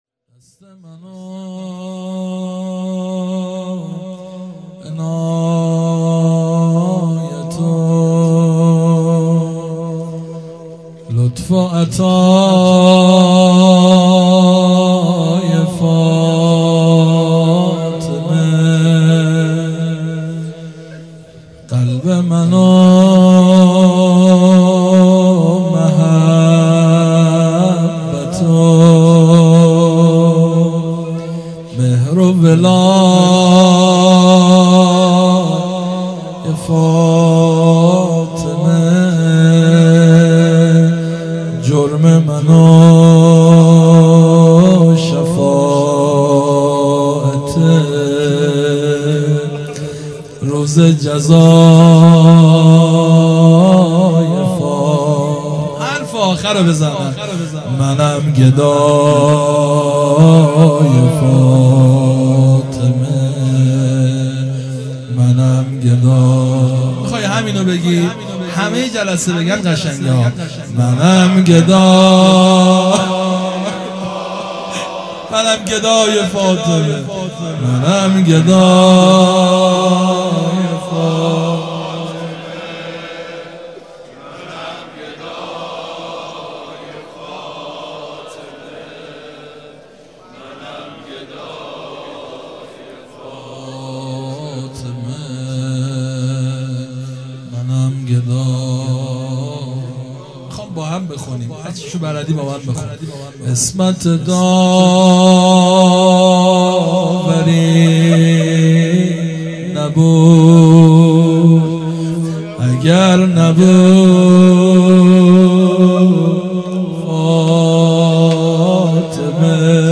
هیئت مکتب الزهرا(س)دارالعباده یزد
0 0 روضه